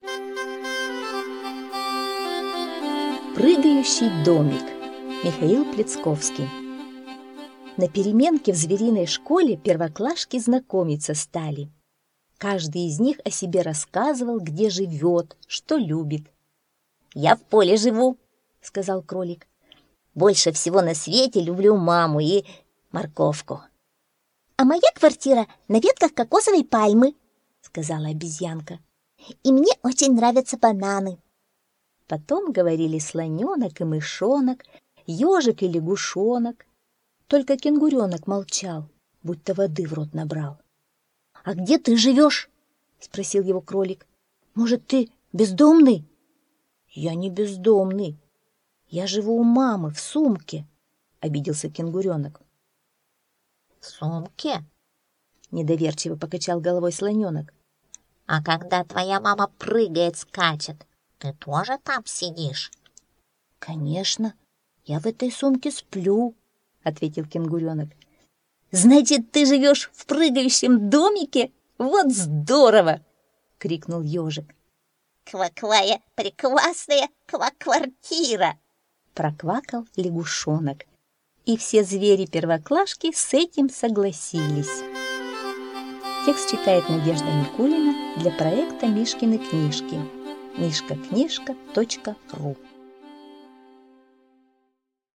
Прыгающий домик - аудиосказка Пляцковского - слушать